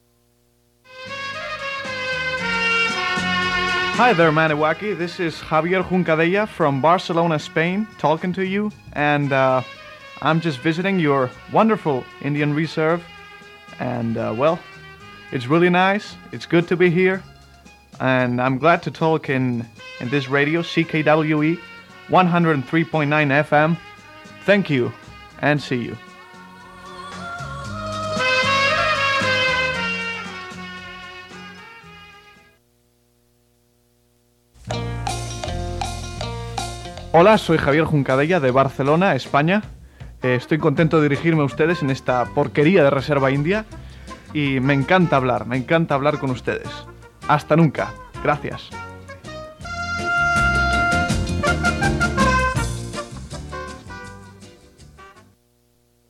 Radio jingles